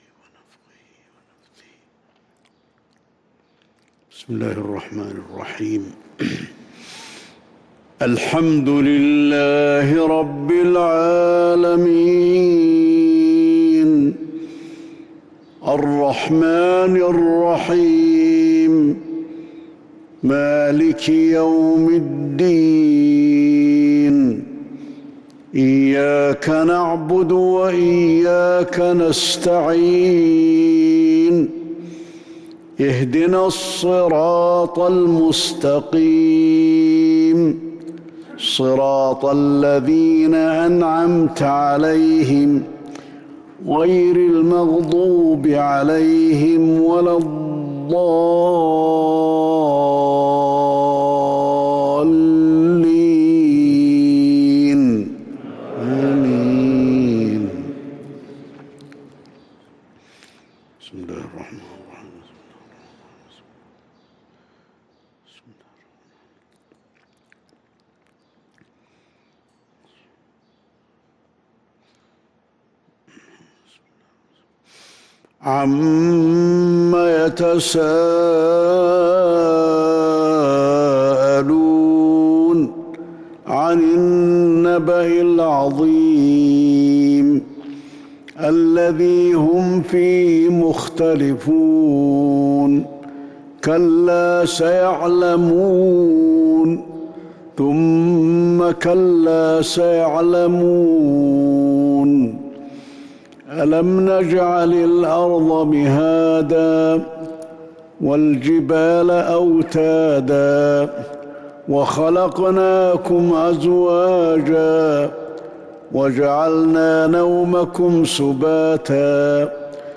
صلاة الفجر للقارئ علي الحذيفي 9 جمادي الأول 1443 هـ